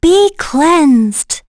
Laias-Vox_Skill1.wav